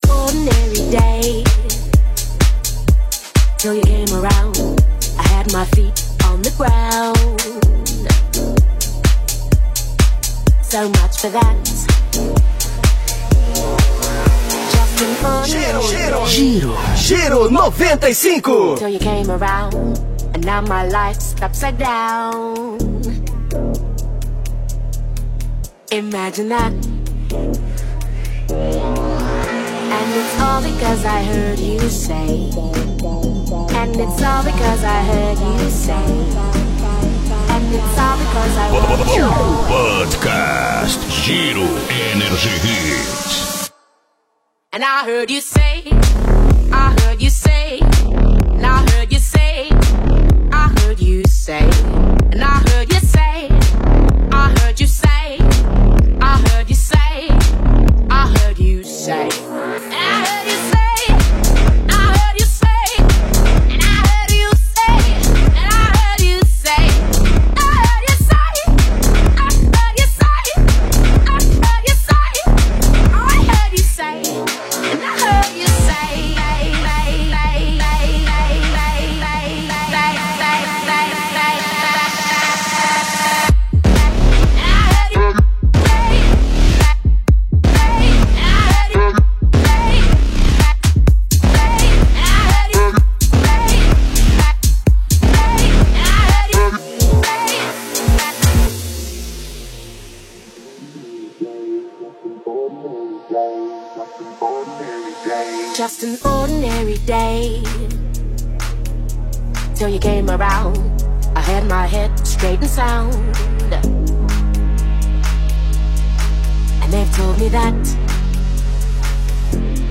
Estilo(s): Deep-House Electro-House